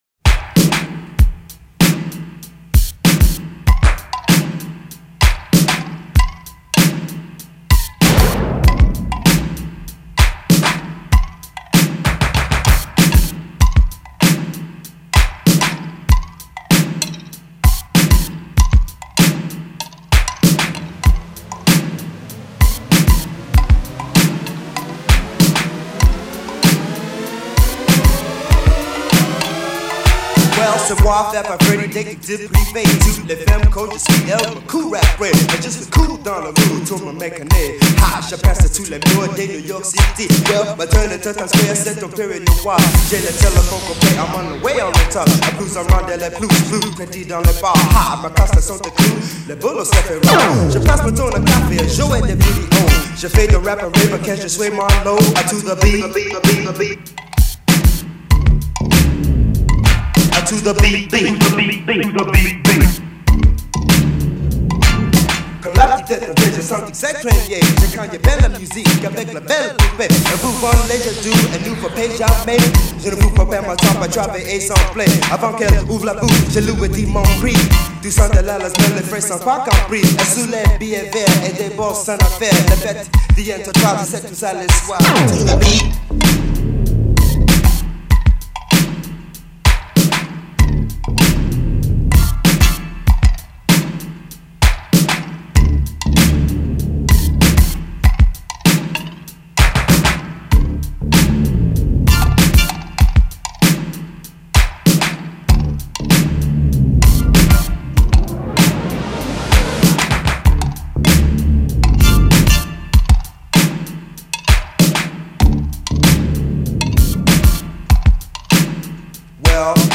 Old-school